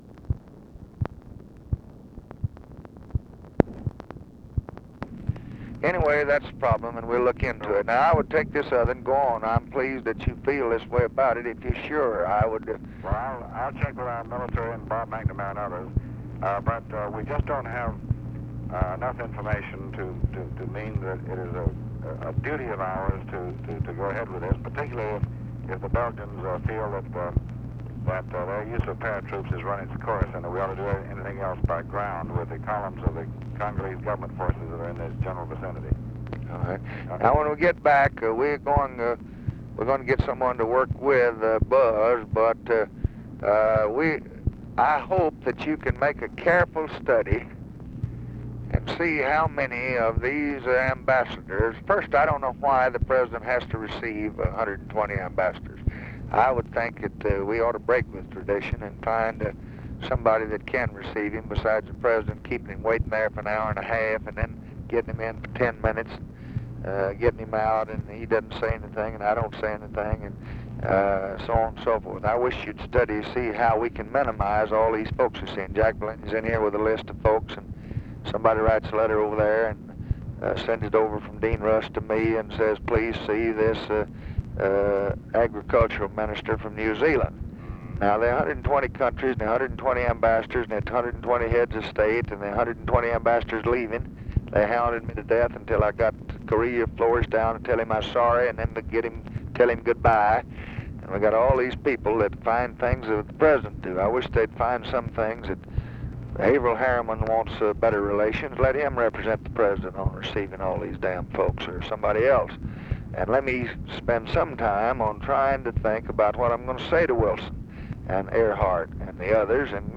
Conversation with DEAN RUSK, November 26, 1964
Secret White House Tapes